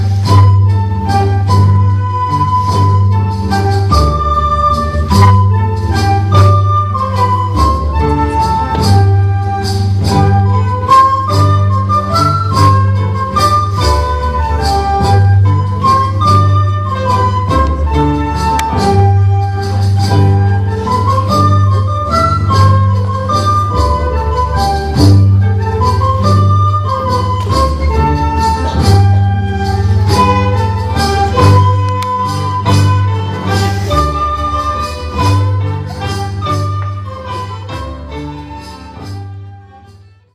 Wie bei der ersten Veranstaltung "Musik im Dorf" vor 4 Jahren beteiligte sich die Schule mit dem Schulchor und mit dem Lehrer-Eltern-Orchester.
Eine kleine H�rprobe vom Lehrer-Eltern-Orchester gibt es hier.